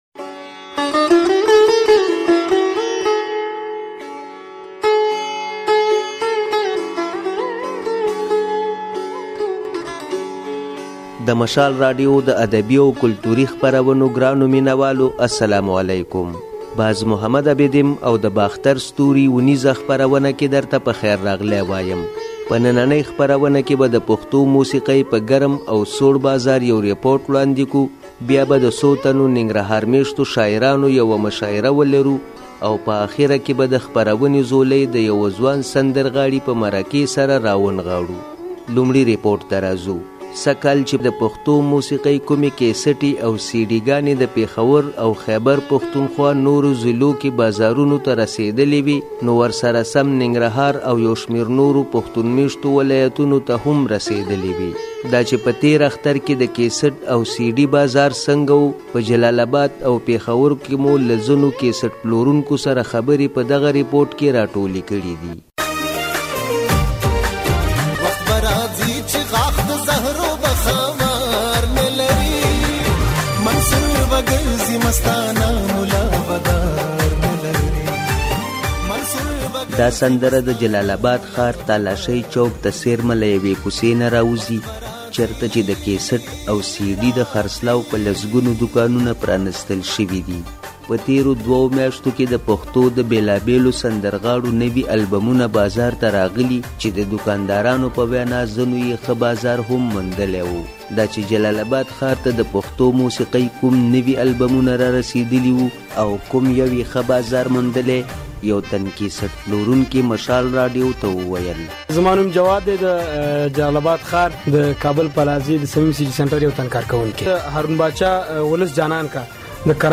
د باختر ستوري په دې خپرونه کې د پښتو موسيقۍ د کيسټو په پر تاوده او ساړه بازار يو راپور، يوه اختريزه مشاعره او له يو ځوان سندرغاړي سره د زړه خواله راټول شوي دي.